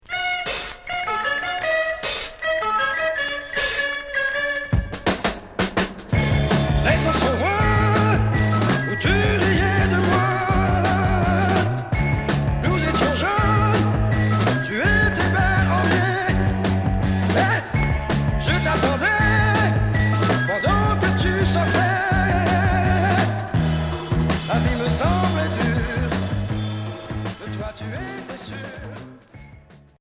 Pop bluesy Premier 45t retour à l'accueil